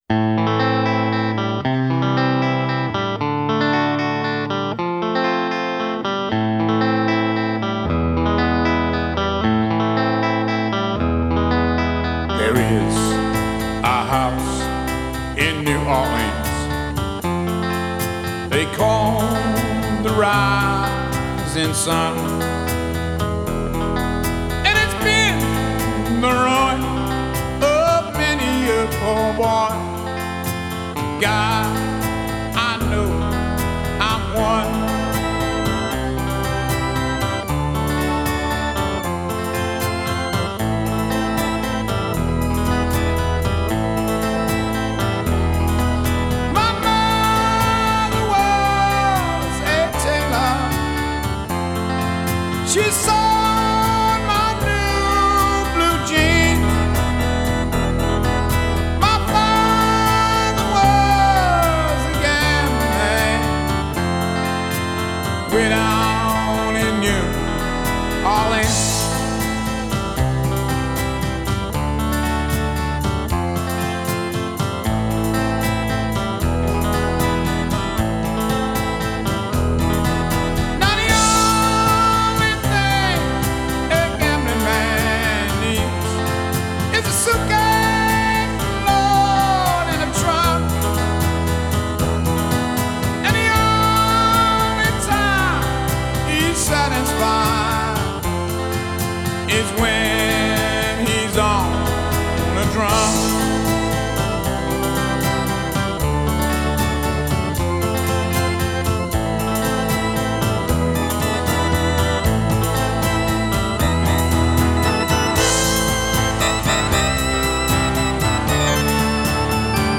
Жанр: психоделический рок, блюз-рок, Ритм-н-блюз, Рок
Genre: Blues, Rock